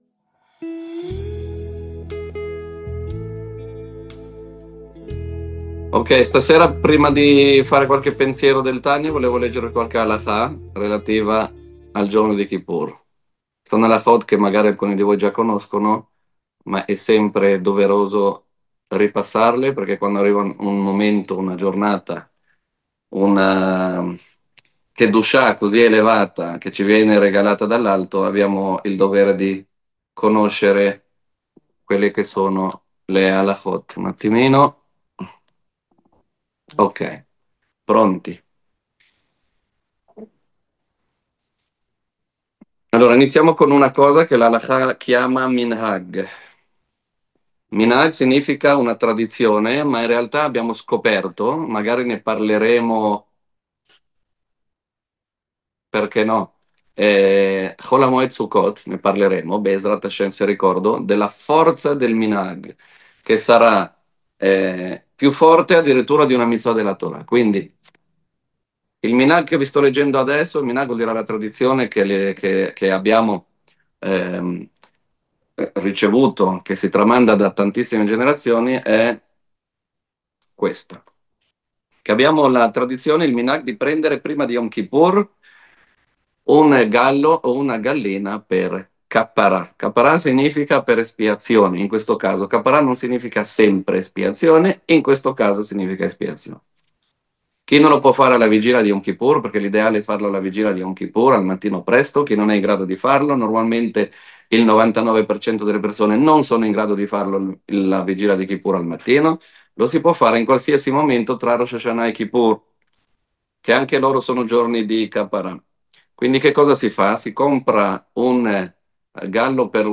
Lezione del 28 settembre 2025